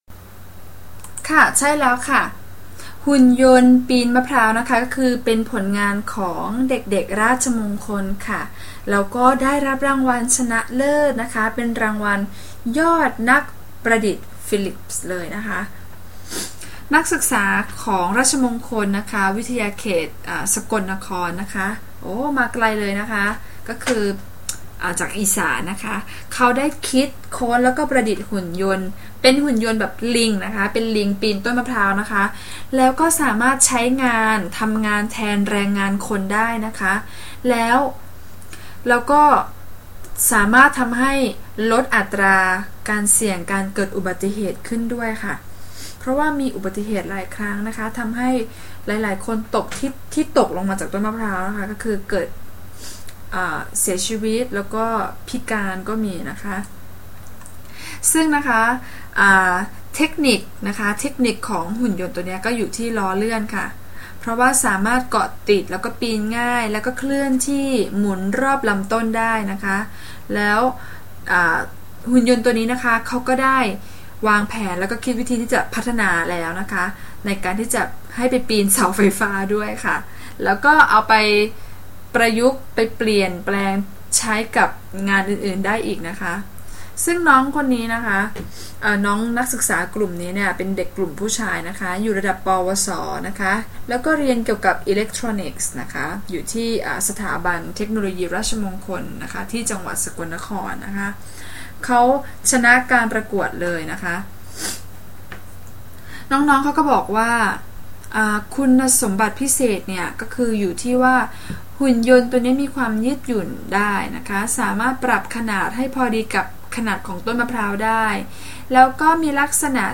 All recordings are unscripted, natural speech and 100% in Thai; they all come with a transcript.
Native speakers